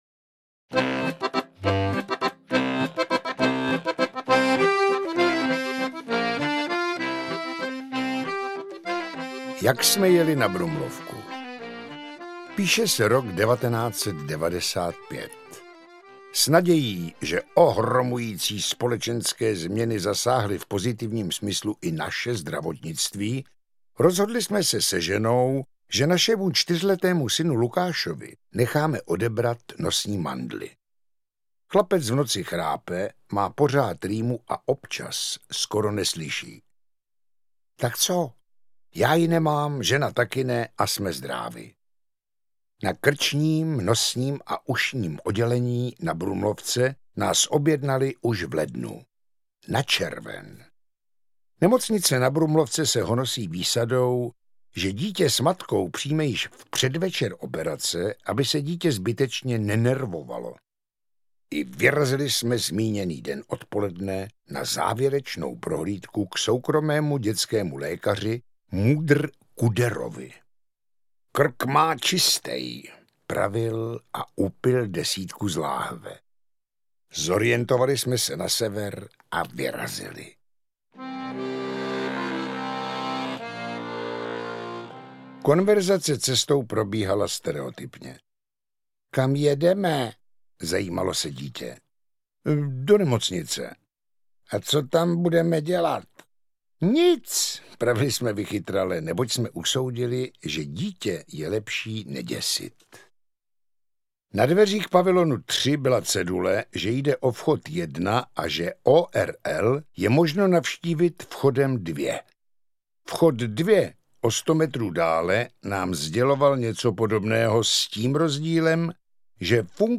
Interpret:  Jiří Krampol
Výběr povídek z úspešné stejnojmenné knihy namluvených Jiřím Krampolem.